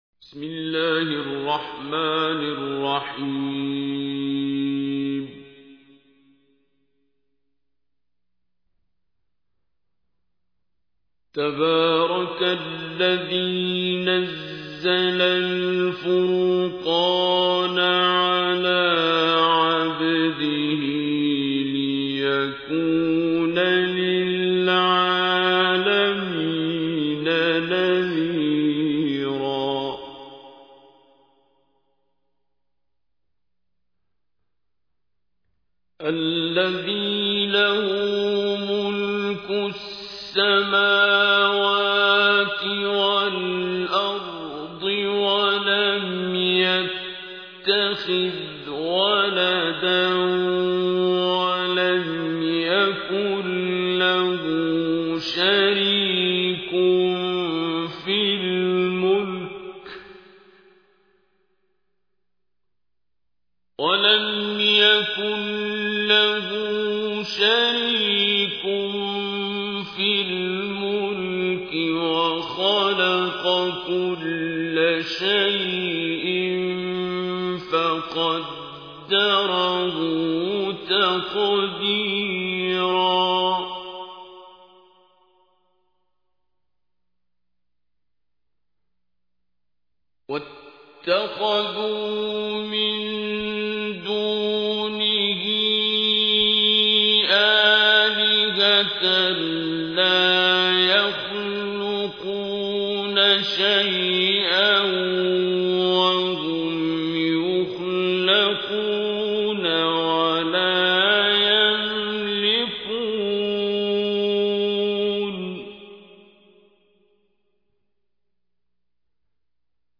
تحميل : 25. سورة الفرقان / القارئ عبد الباسط عبد الصمد / القرآن الكريم / موقع يا حسين